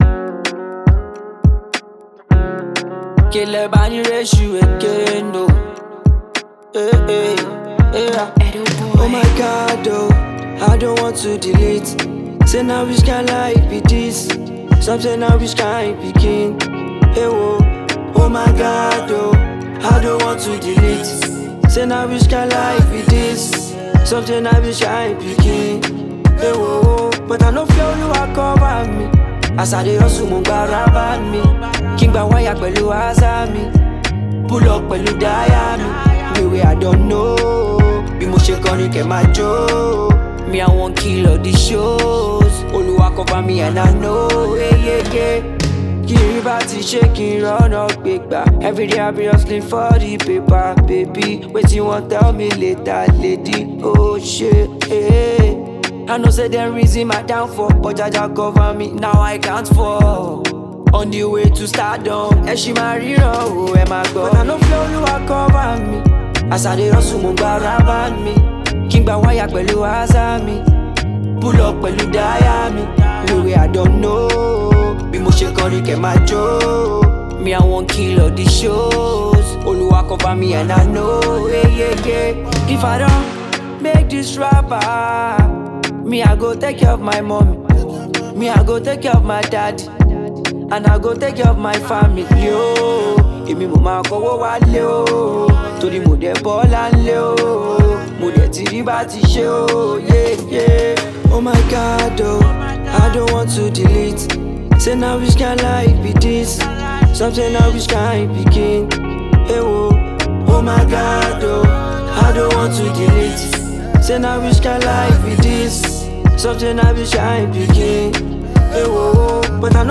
Talented afrobeats singer
soul-stirring anthem